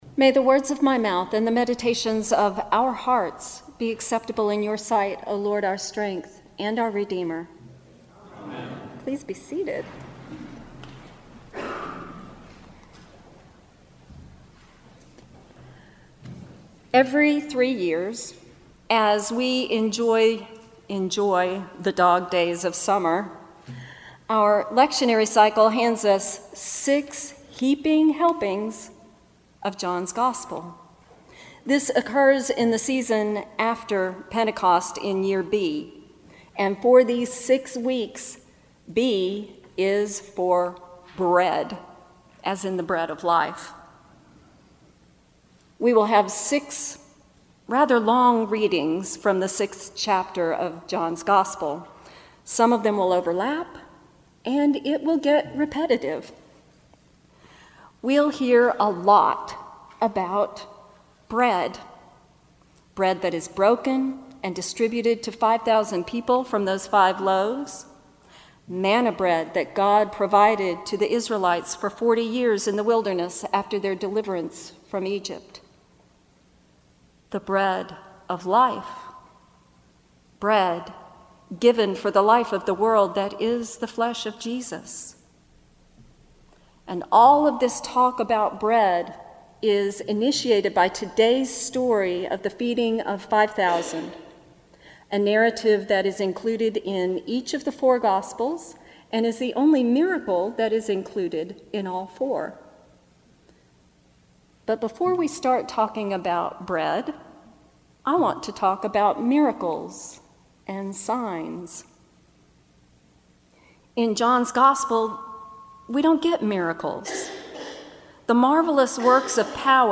Homily
at the 10:30 am Holy Eucharist service.